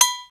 Index of /kb6/Akai_XR-10/Percussion
Agogo.wav